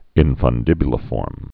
(ĭnfən-dĭbyə-lə-fôrm)